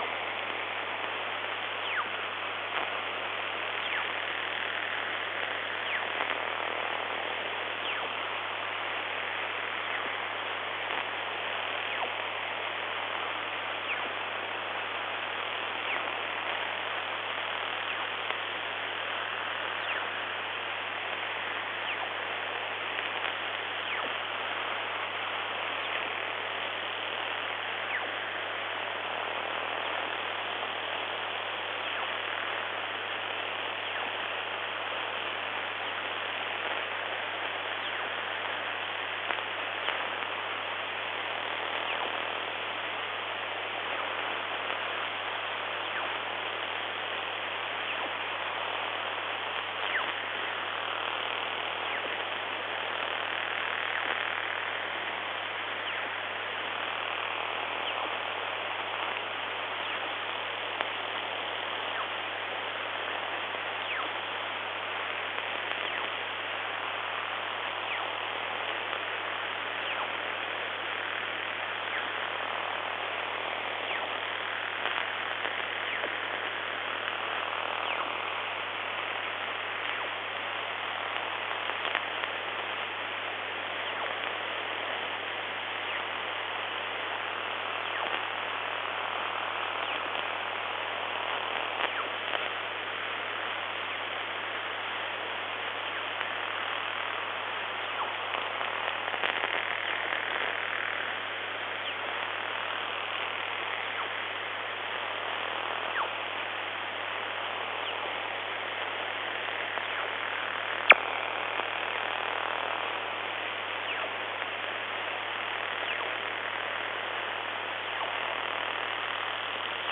haarp_france_audio.mp3